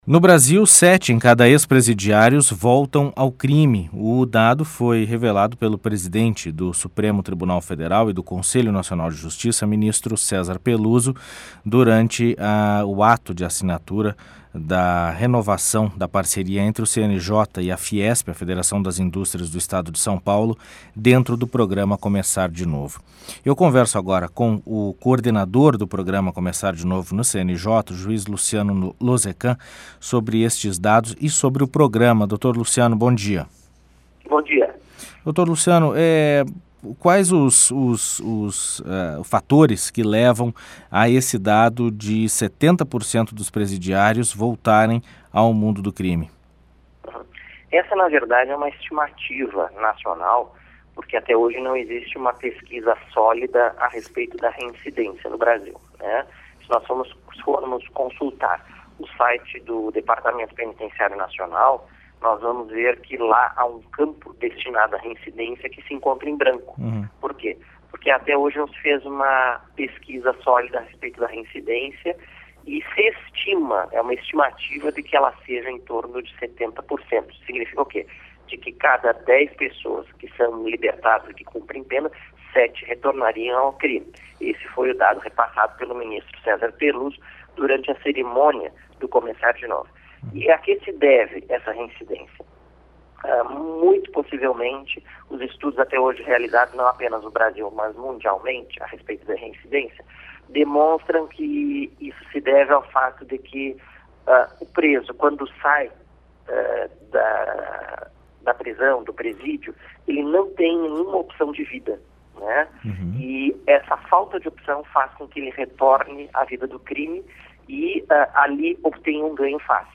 Ex-presidiários voltam ao crime por falta de oportunidade, aponta CNJ Entrevista com o juiz Luciano Losekan, coordenador do programa "Começar de Novo", do Conselho Nacional de Justiça.